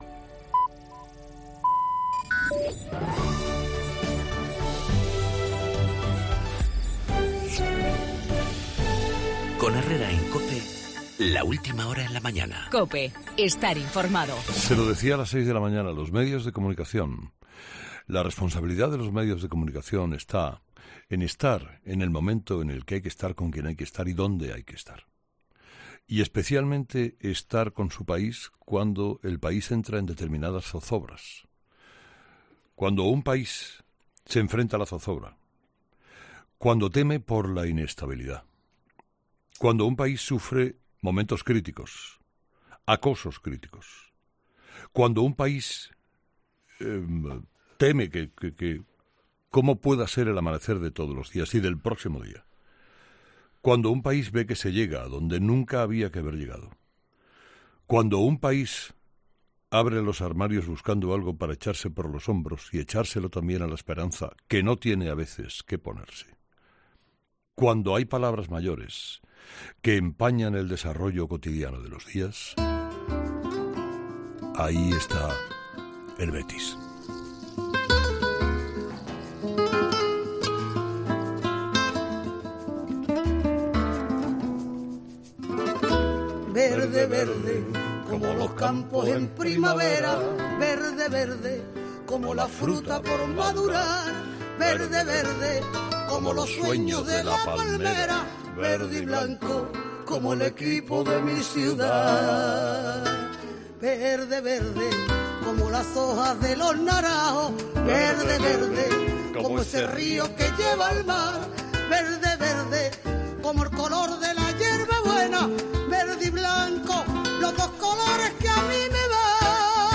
Monólogo de las 8 de Herrera
La Guardia Civil detiene a 14 personas y hace 41 registros para evitar el referéndum, en el editorial de Carlos Herrera